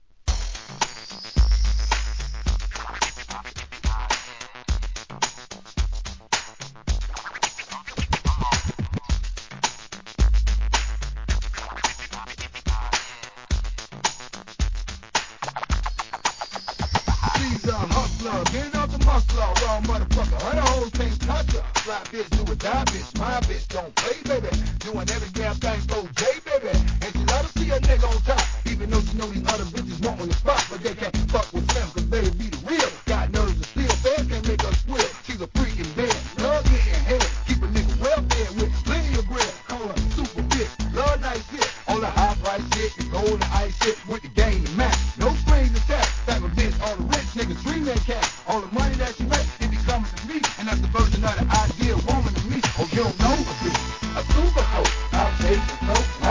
HIP HOP/R&B
電子音炸裂な人気作REMIX!!!